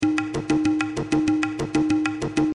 Tag: 96 bpm Ethnic Loops Tabla Loops 434.53 KB wav Key : Unknown